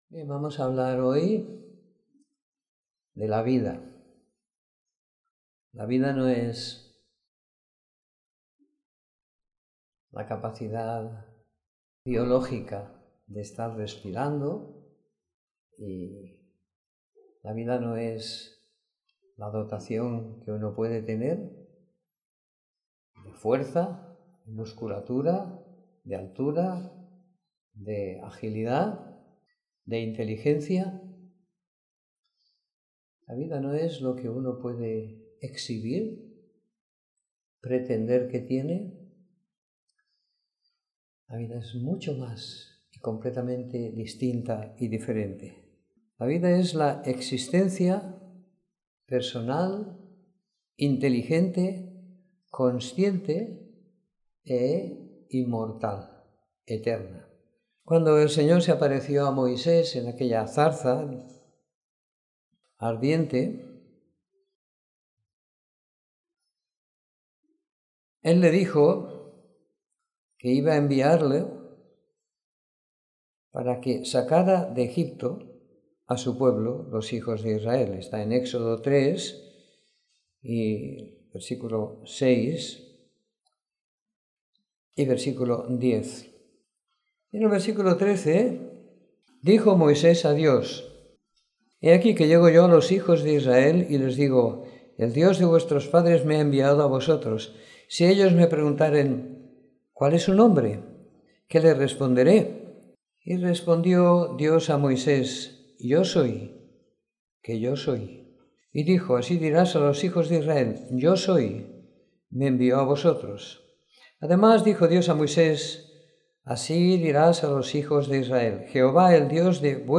Reunión semanal de Predicación del Evangelio.